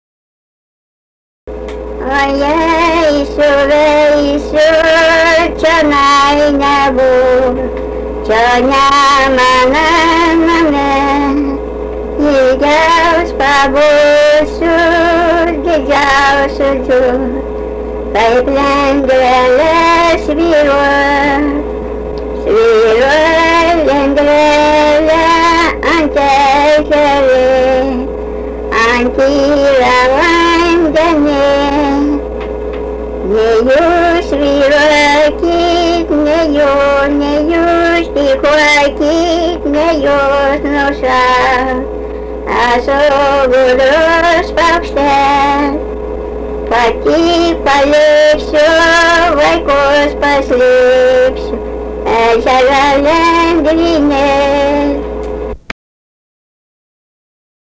daina, šeimos
vokalinis